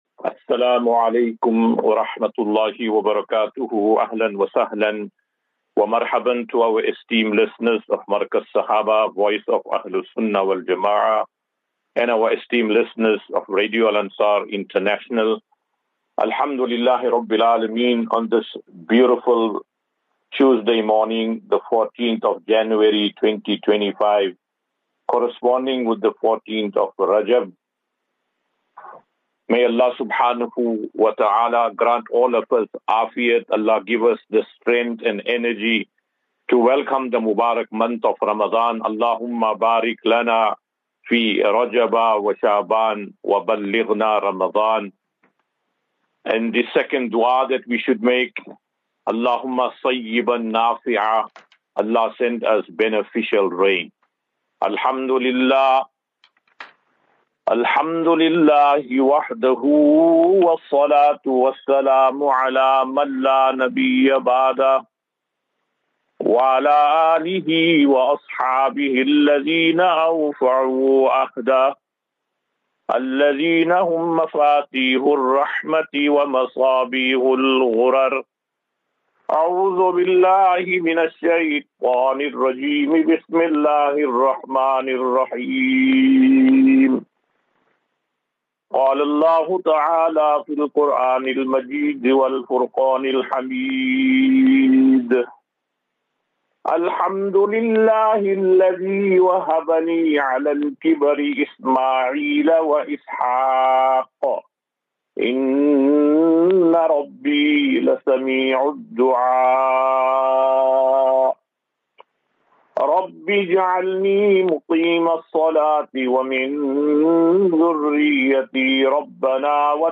14 Jan 14 January 2025. Assafinatu - Illal - Jannah. QnA.